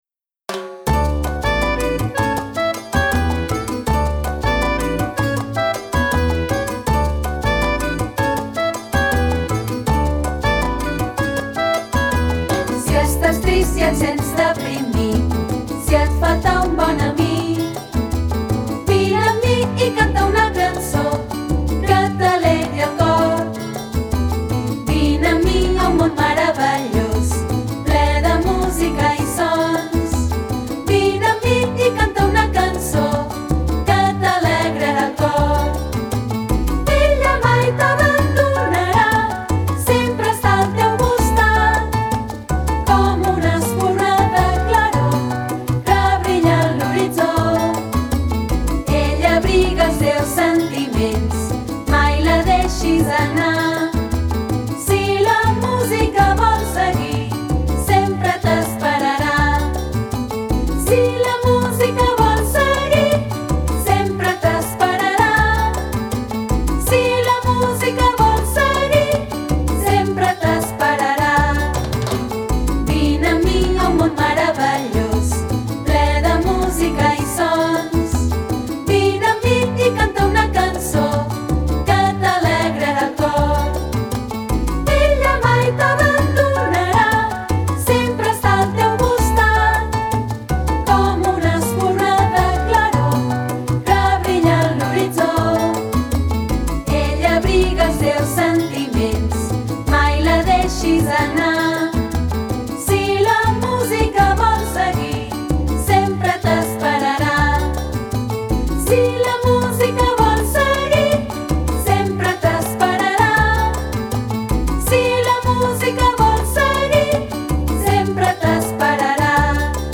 S’acosta Santa Cecília, patrona de la música, i amb els nens i nenes de l’escola estem preparant aquesta cançó tan animada que parla de la música… si la música vols seguir, sempre t’esperarà.